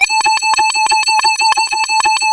OSCAR 14 A#2.wav